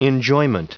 Prononciation du mot enjoyment en anglais (fichier audio)